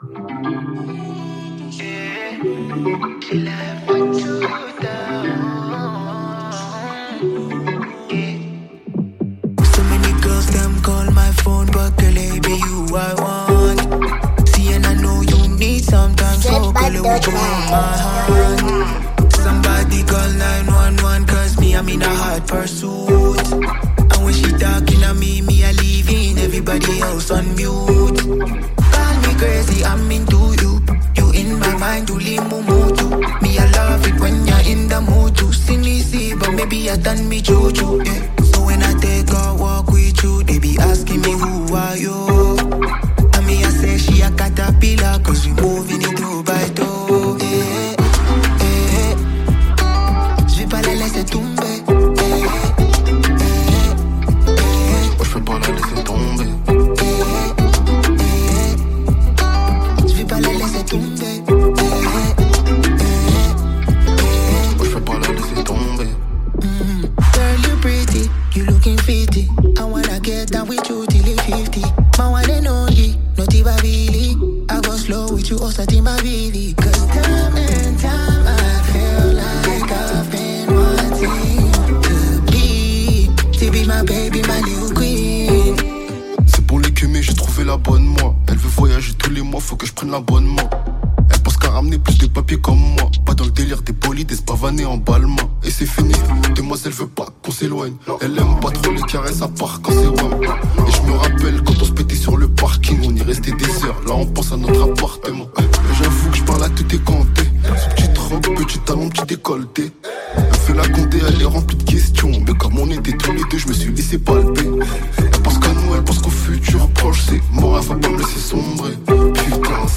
upbeat music